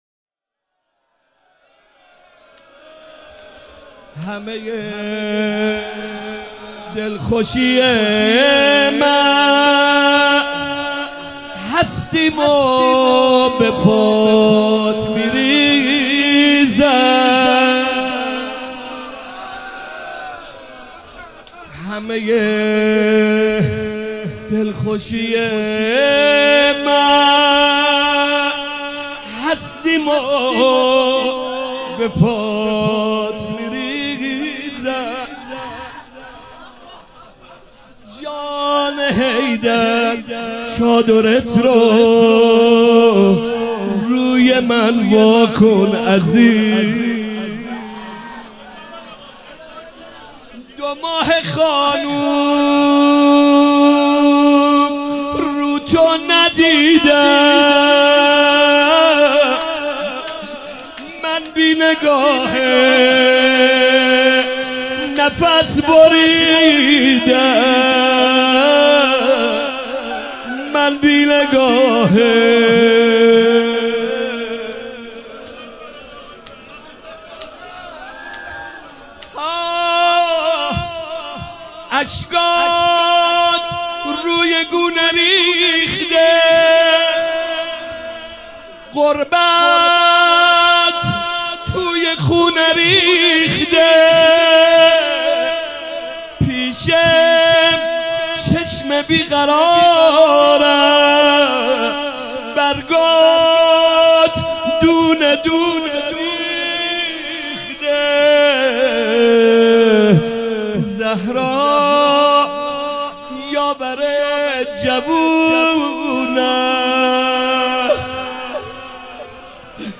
روضه